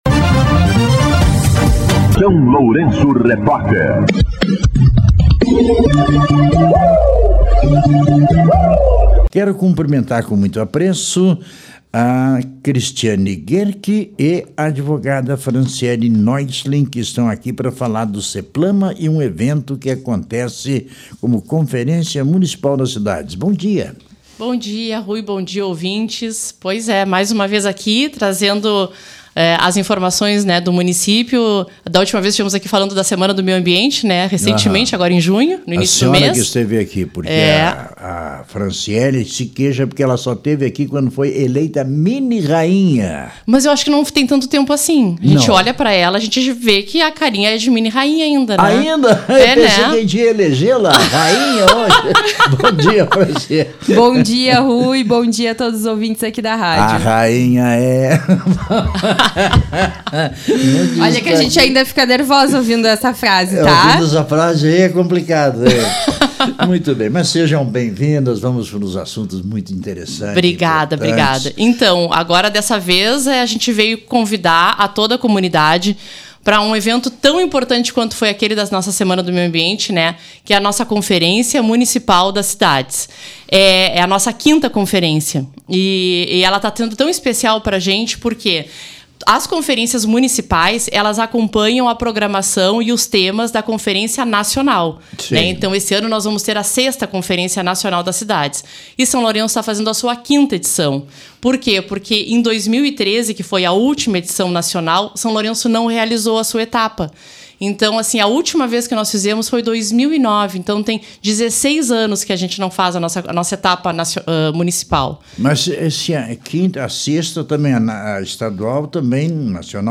Entrevista com a Secretária